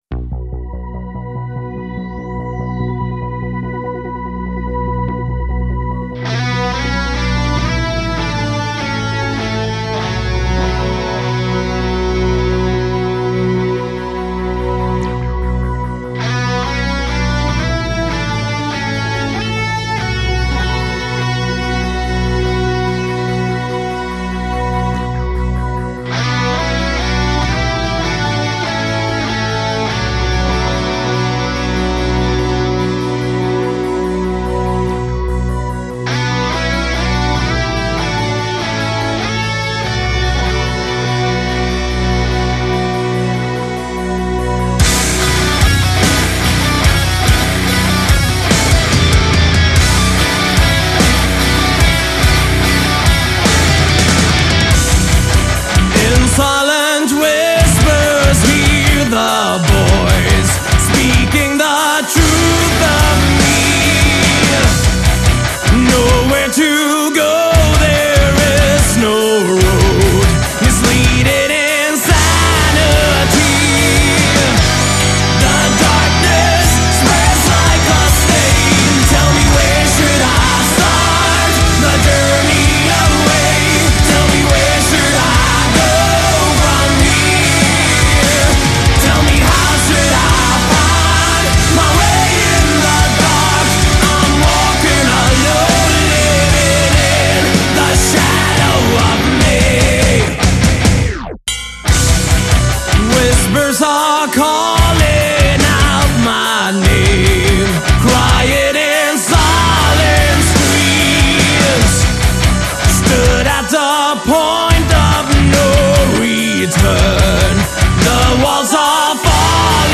Жанр: Power Metal